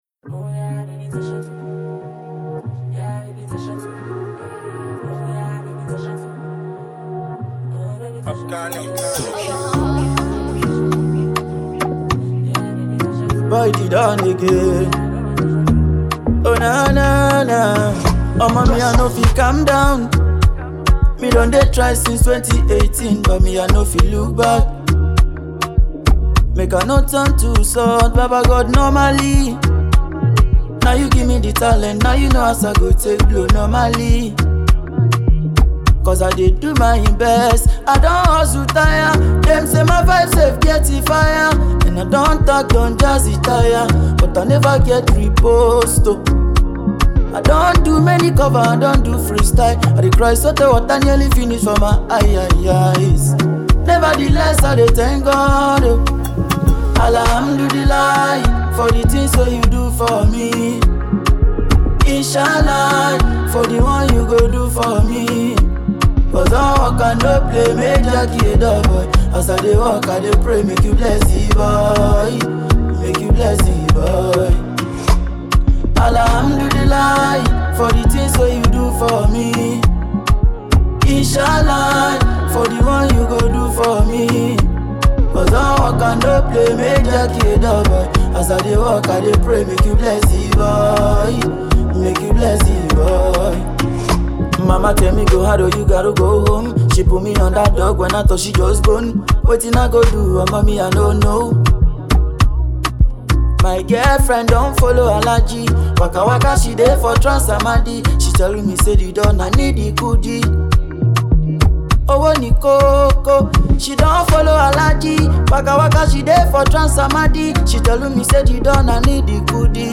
A Fast-Rising Afrobeats Artist Shaping a New Sound.